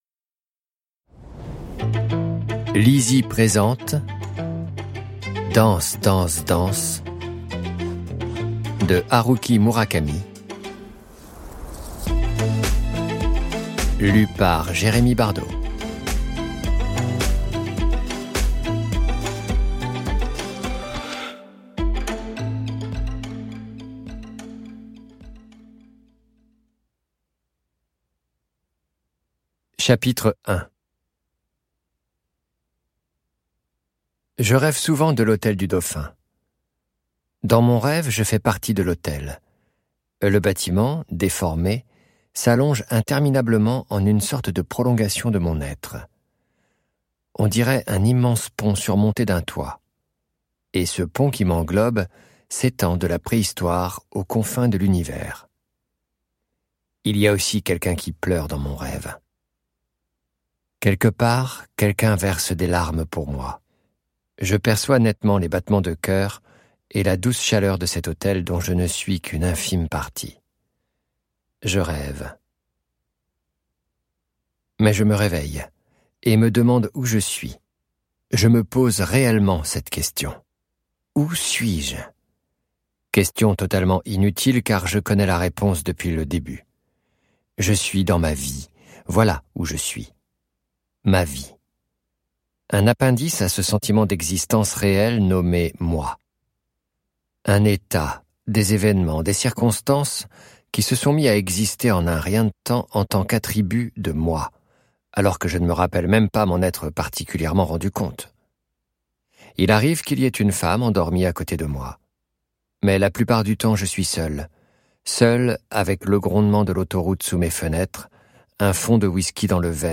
Click for an excerpt - Danse, danse, danse de Haruki MURAKAMI